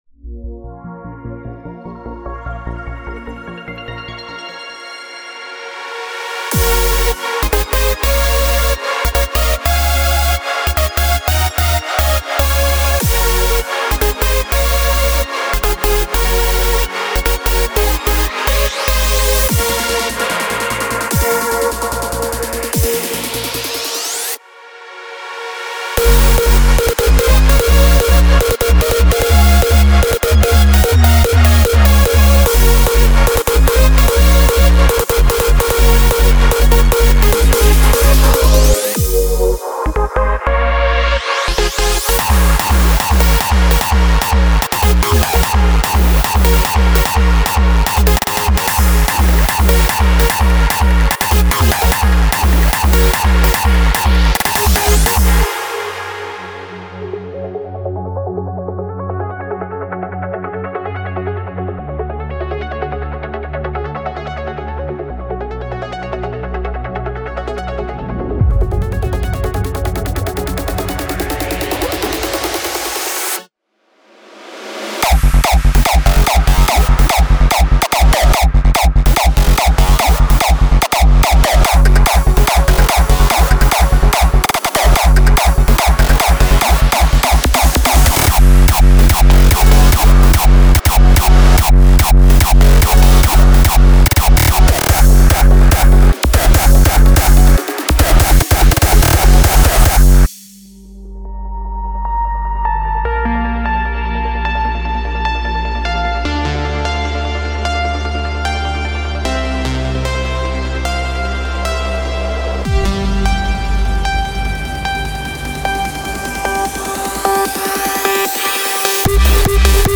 Type: Midi Samples
Hardcore / Hardstyle
20 x Bass Loops [150BPM – 155BPM]